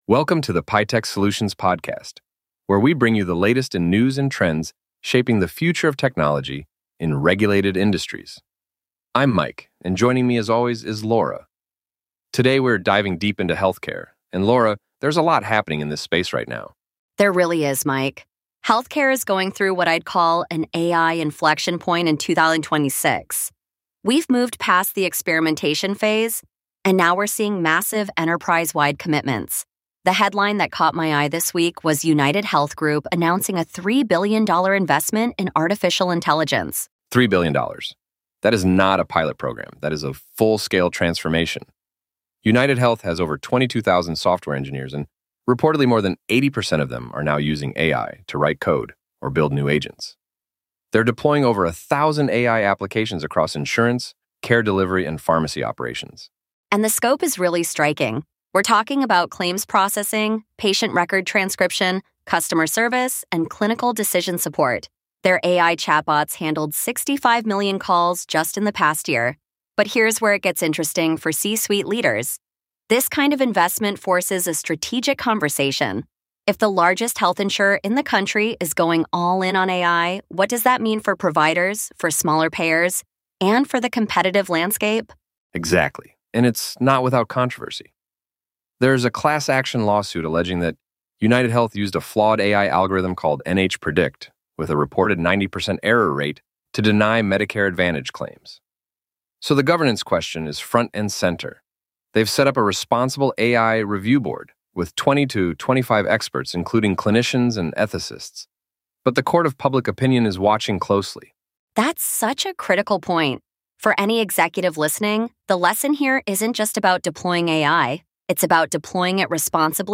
The hosts examine UnitedHealth Group massive AI commitment, deploying over one thousand AI applications across insurance, care delivery, and pharmacy operations.